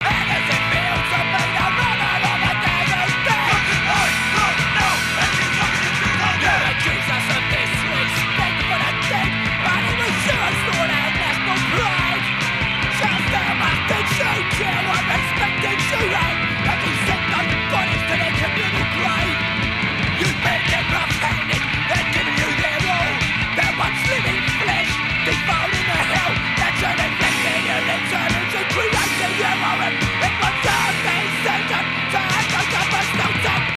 Stereo excerpt